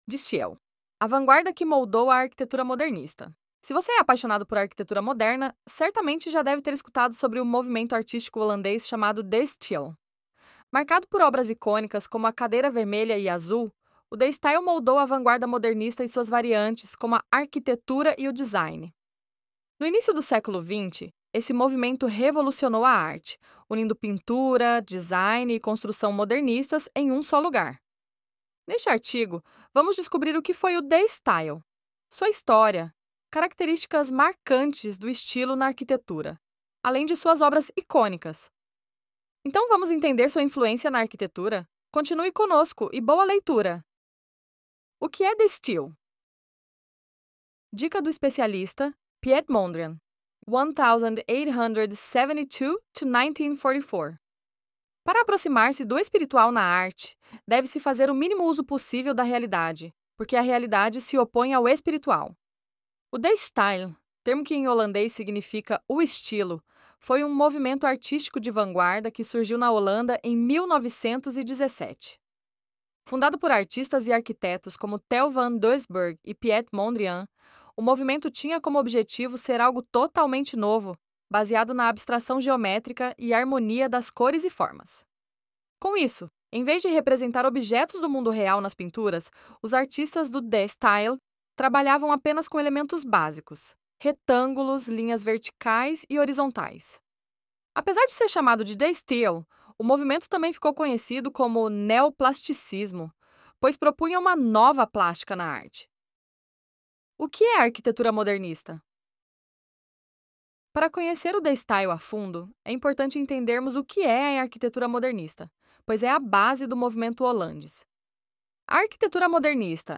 🎧 Sem tempo para ler? Então, dê um play e escute nosso artigo!